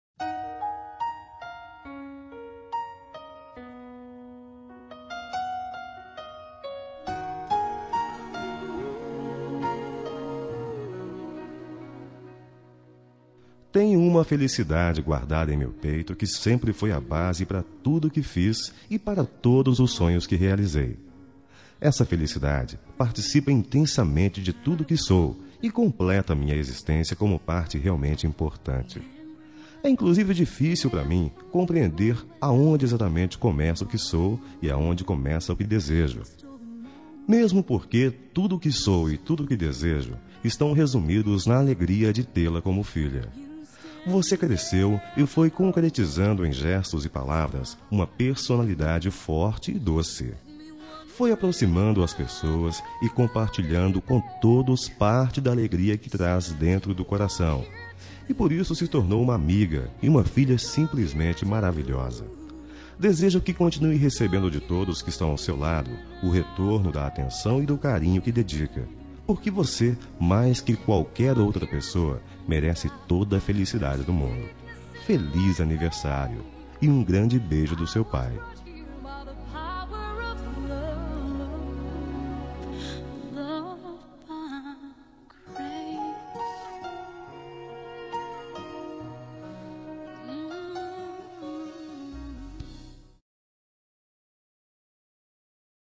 Telemensagem de Aniversário de Filha – Voz Masculina – Cód: 1793